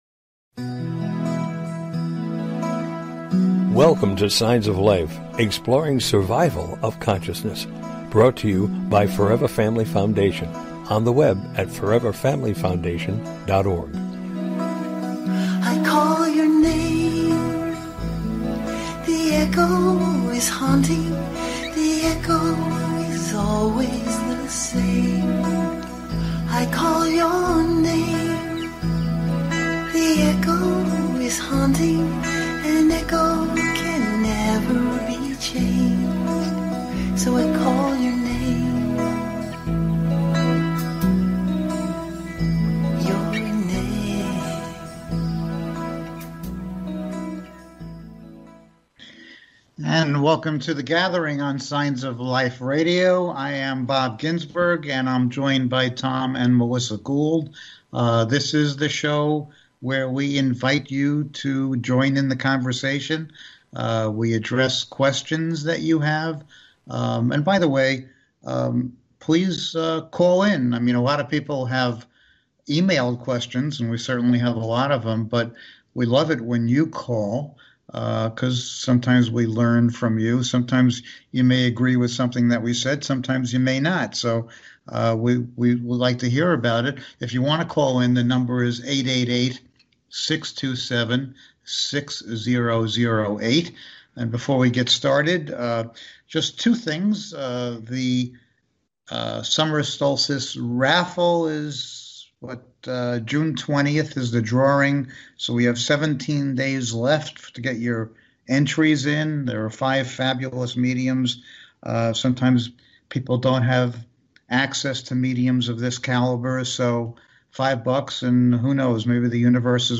The Gathering: A Discussion about After Life Communication